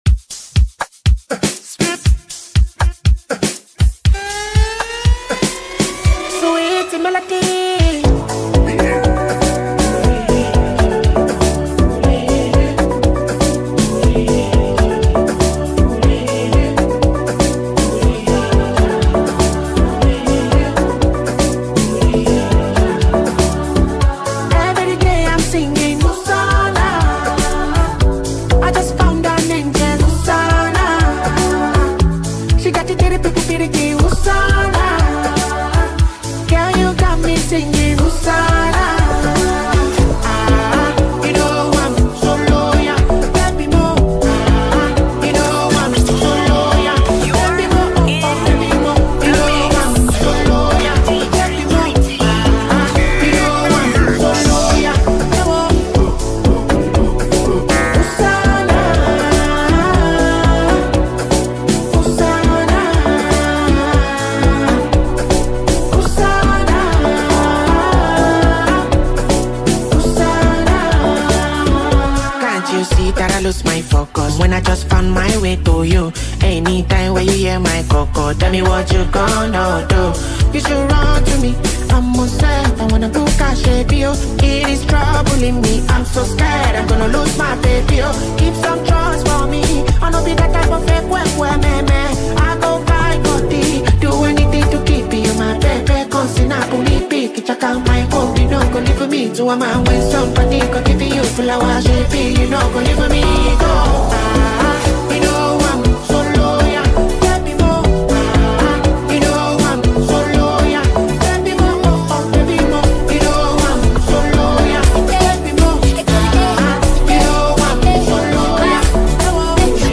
Afrobeat Music